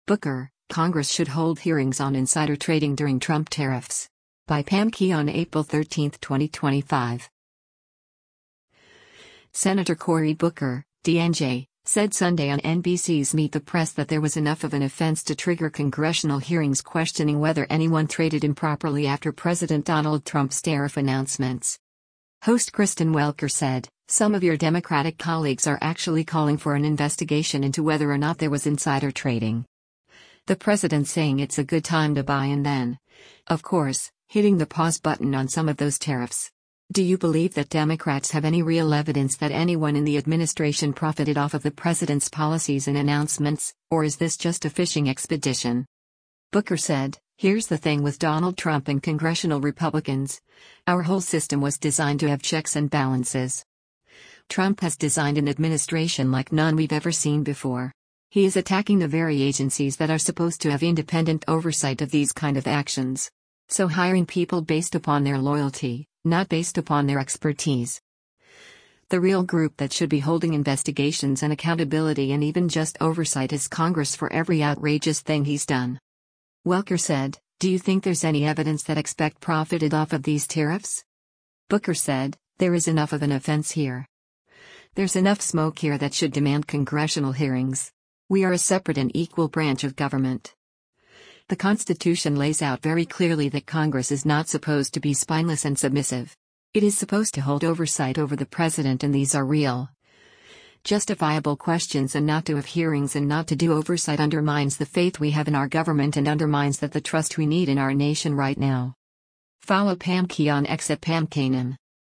Senator Cory Booker (D-NJ) said Sunday on NBC’s “Meet the Press” that there was “enough of an offense” to trigger congressional hearings questioning whether anyone traded improperly after President Donald Trump’s tariff announcements.